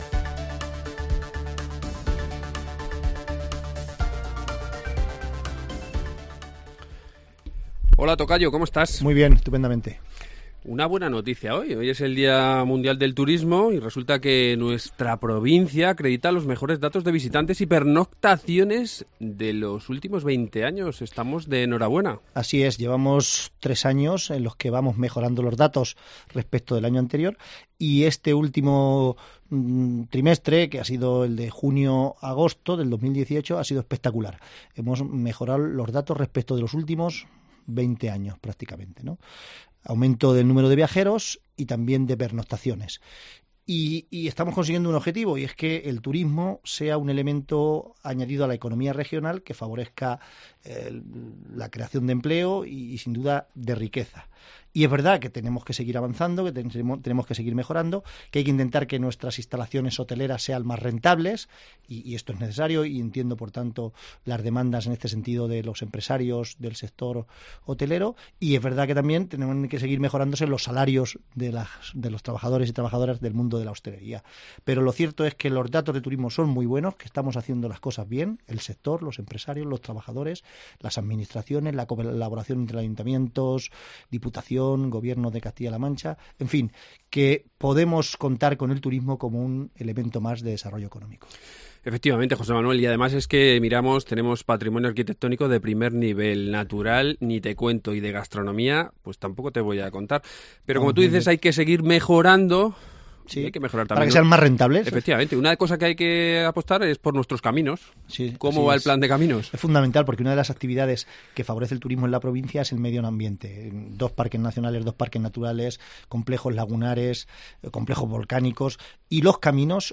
Hoy nos acompaña en nuestros estudios el presidente de la Diputación, José Manuel Caballero, y con él hablamos sobre las últimas convocatorias de la Diputación y también de toda la actualidad provincial.